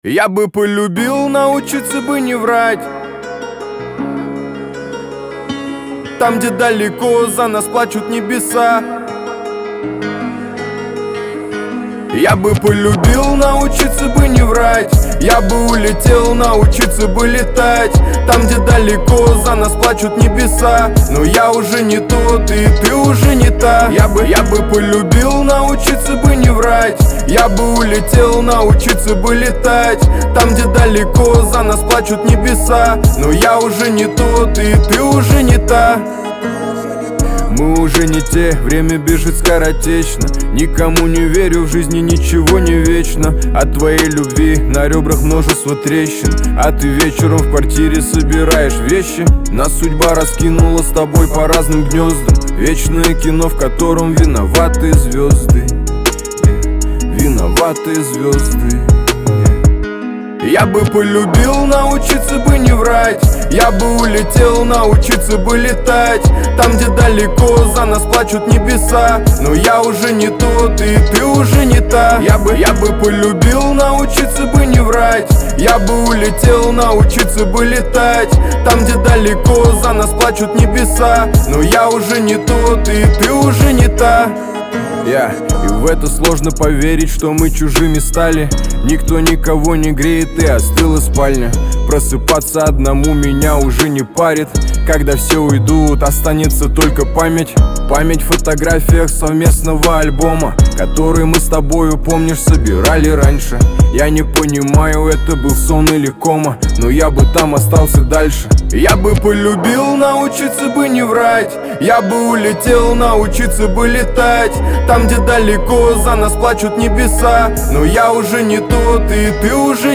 Трек размещён в разделе Русские песни / Поп / 2022.